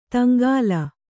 ♪ taŋgāla